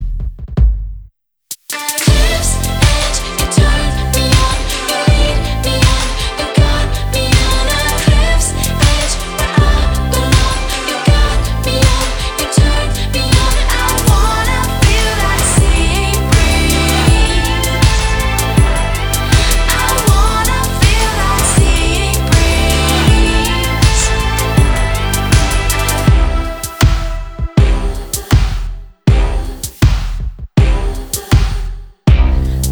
• Качество: 320, Stereo
громкие
женский вокал
мелодичные
dance
club
красивый женский голос
звонкие
vocal